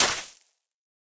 Minecraft Version Minecraft Version latest Latest Release | Latest Snapshot latest / assets / minecraft / sounds / block / suspicious_sand / step4.ogg Compare With Compare With Latest Release | Latest Snapshot
step4.ogg